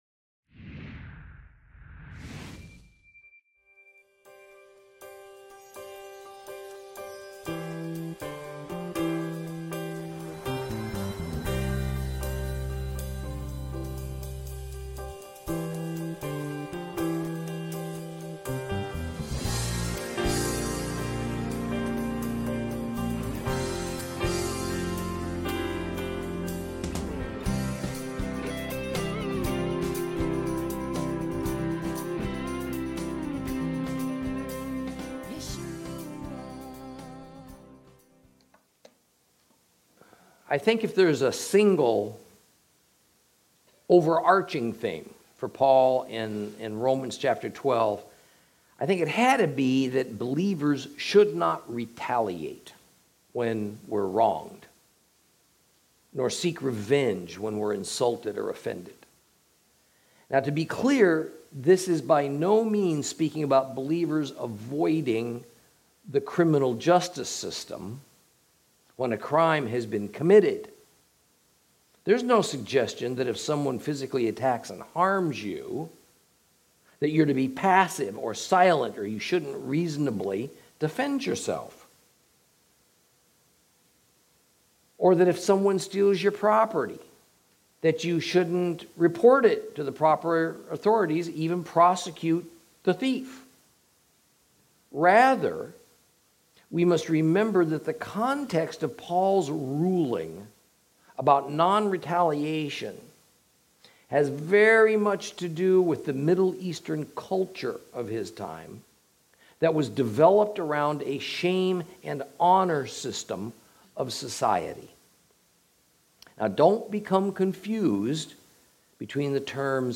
Lesson 31 Ch13 - Torah Class